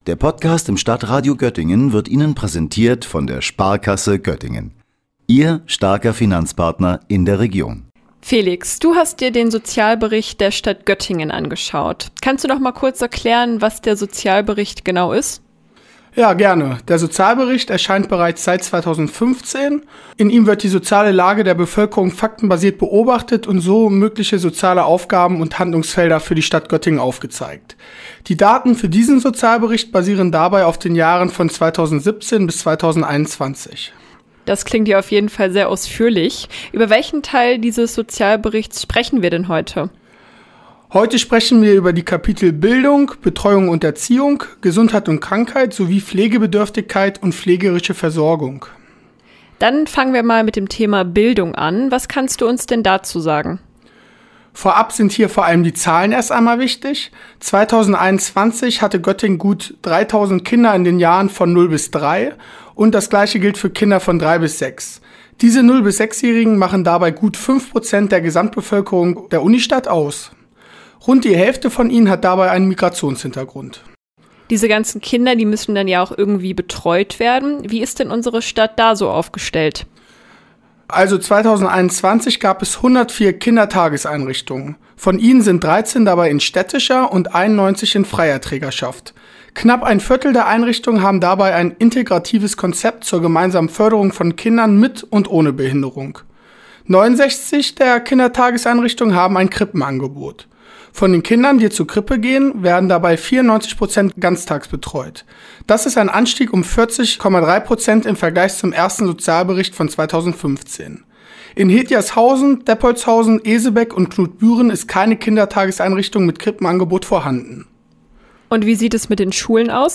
Gesundheit, Sportvereine und Bildung. Gespräch über den Sozialbericht der Stadt Göttingen